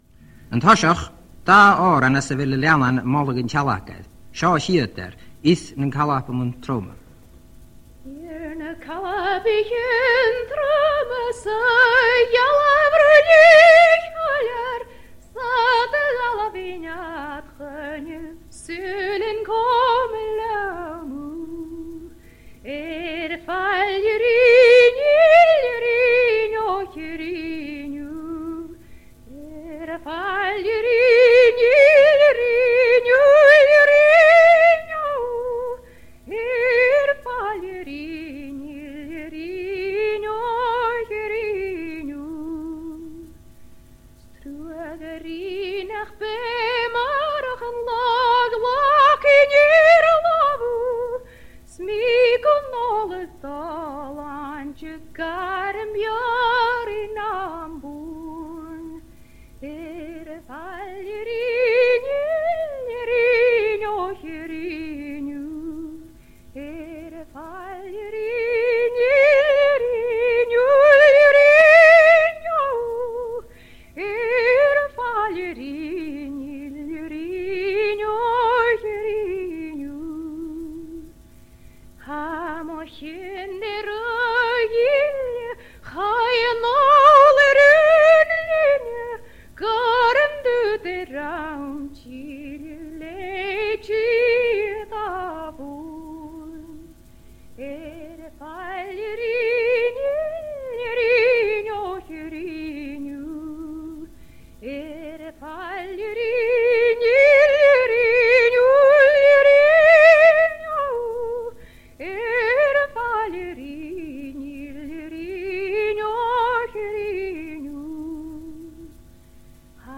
These songs are from a Gaelic radio broadcast from around 1960